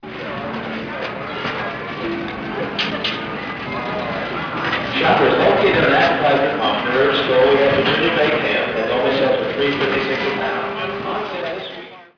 Field Recording 4
General bustle of a grocery store, and a sale announcement over the store’s intercom
Grocery-Store.mp3